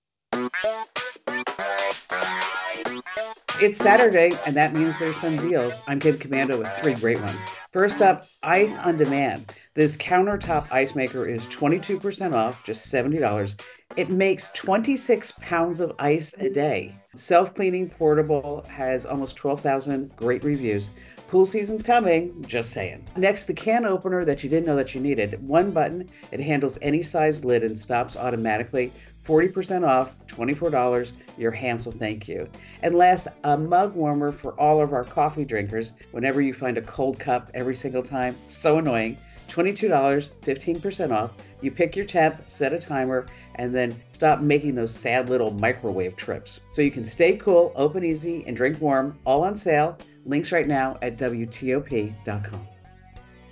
Kim Komando breaks downs some of the top ‘Saturday Steals’